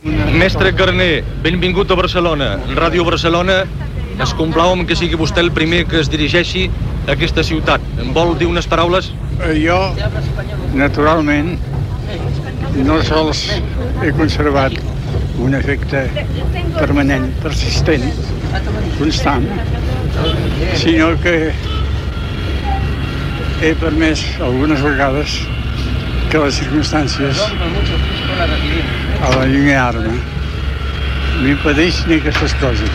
Paraules de l'escriptor Josep Carner a l'aeroport en retornar de l'exili.
Informatiu